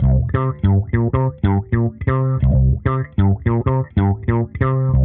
Index of /musicradar/dusty-funk-samples/Bass/95bpm